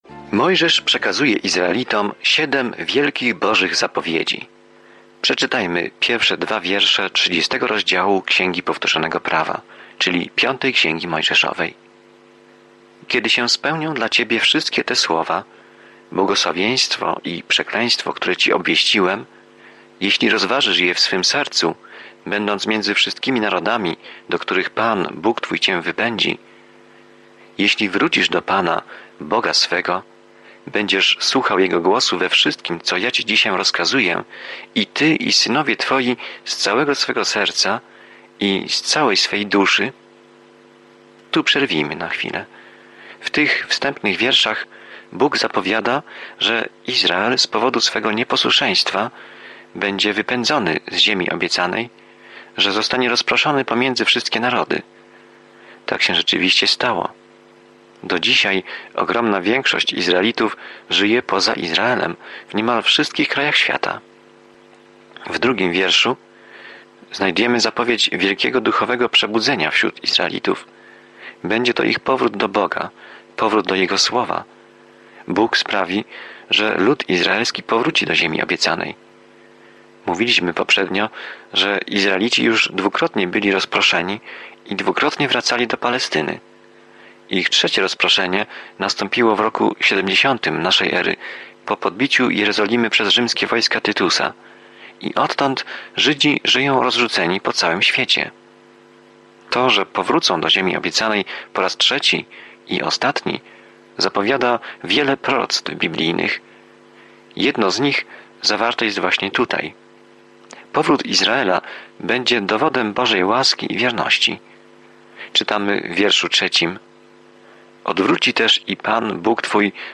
Pismo Święte Powtórzonego Prawa 30 Powtórzonego Prawa 31:1-8 Dzień 16 Rozpocznij ten plan Dzień 18 O tym planie Księga Powtórzonego Prawa podsumowuje dobre prawo Boże i uczy, że posłuszeństwo jest naszą odpowiedzią na Jego miłość. Codziennie podróżuj po Księdze Powtórzonego Prawa, słuchając studium audio i czytając wybrane wersety słowa Bożego.